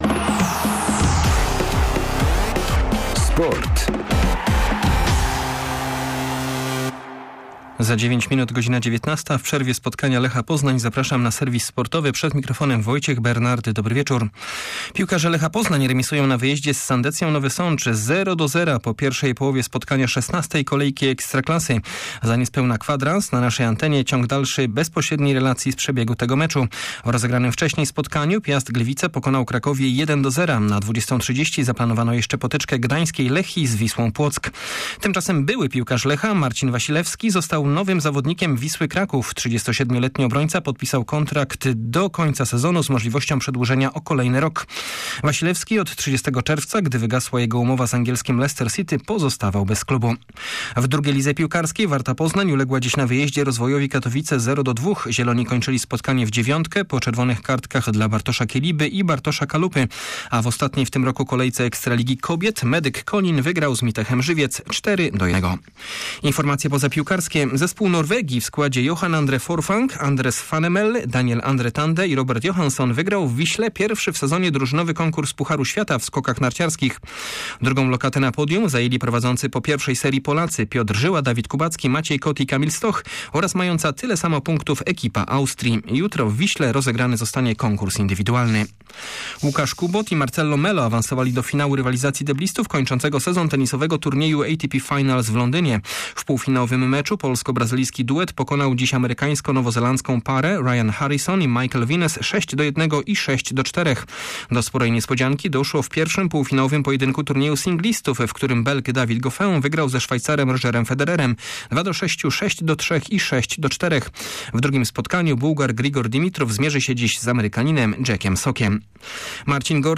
18.11 serwis sportowy godz. 18:50